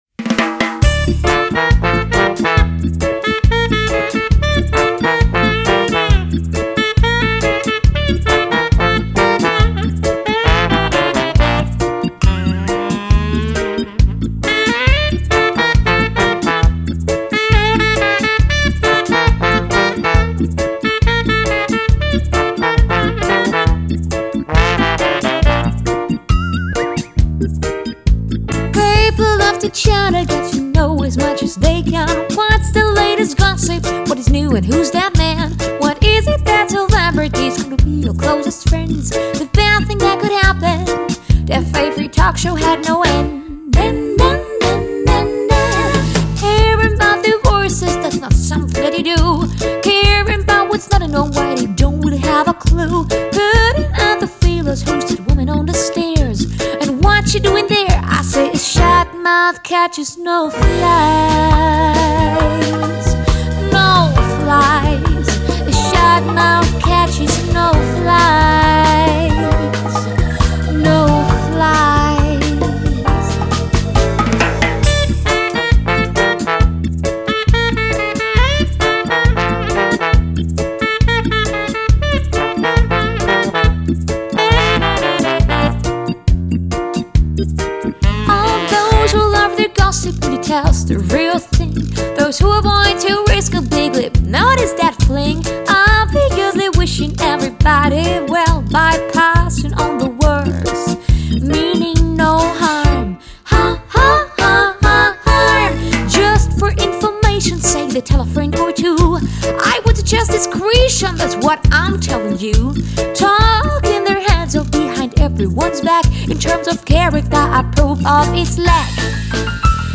Gesang
Keys
E-Bass
Drums